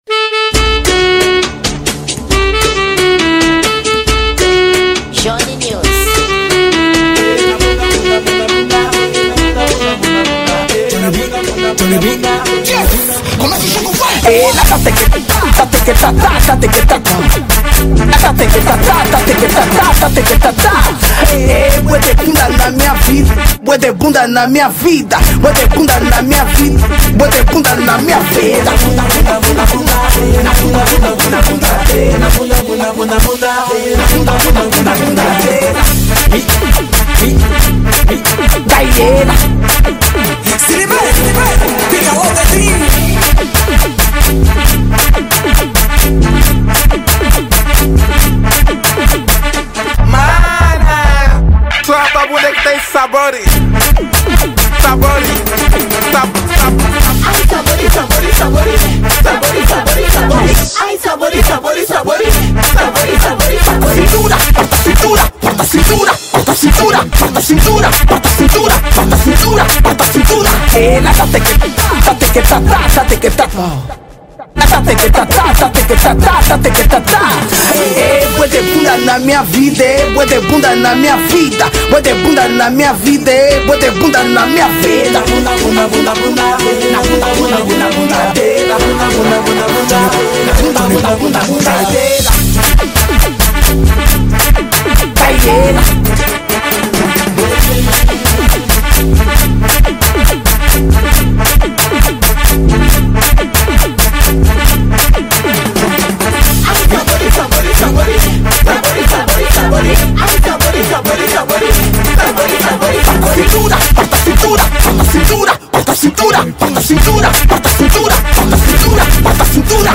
Kuduro Ano de Lançamento